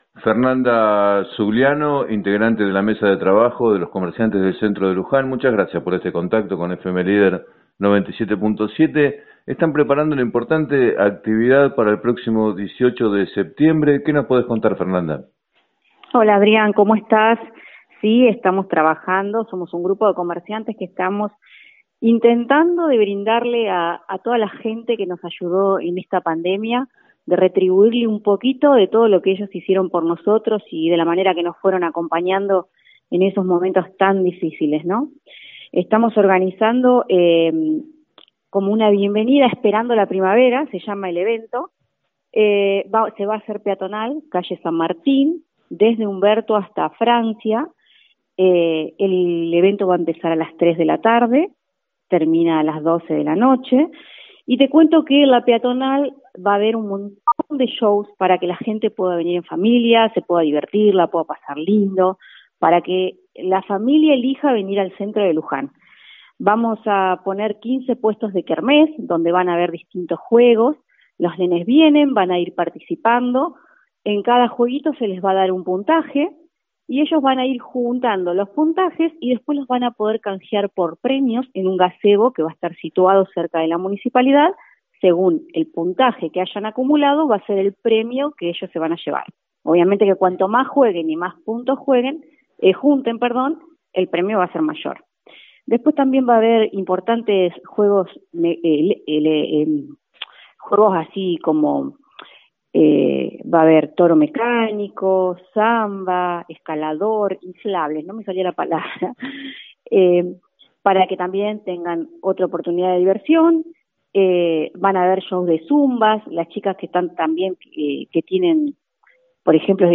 En declaraciones al programa “7 a 9” de FM Líder 97.7